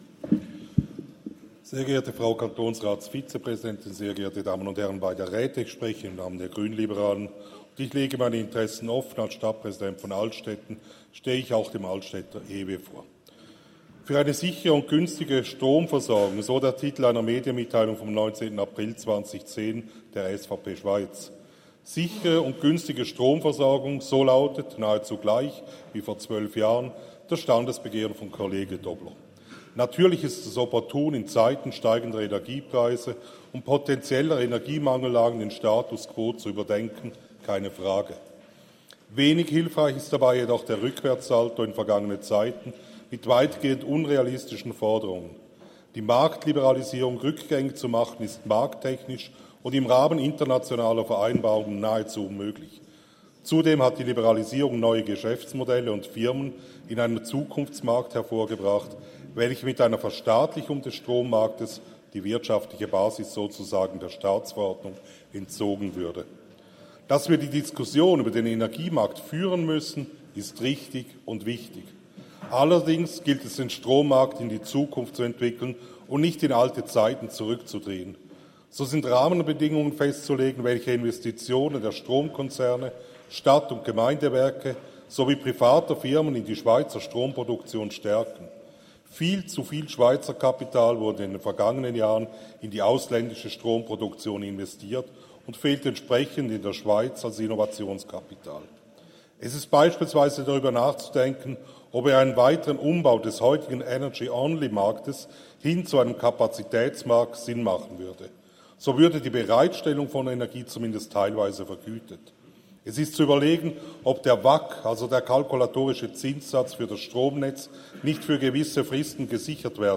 Session des Kantonsrates vom 18. bis 20. September 2023, Herbstsession